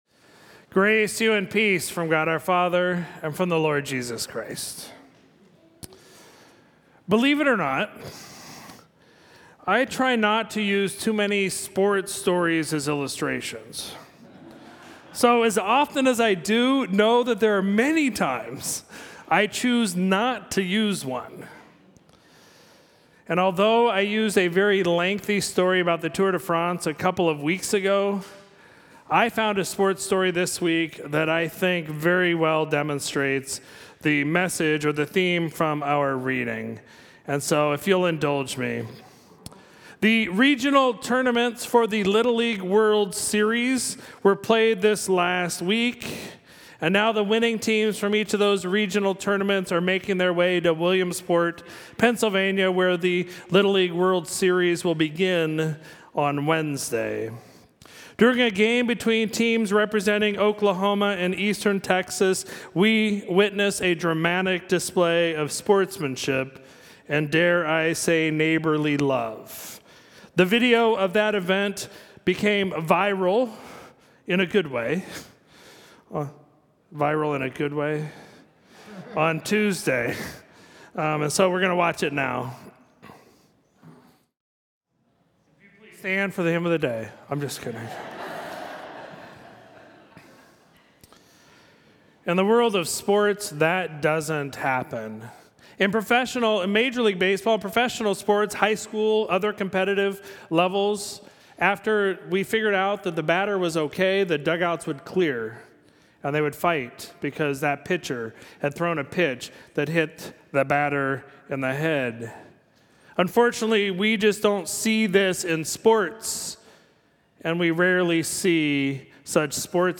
Sermon for Sunday, August 14, 2022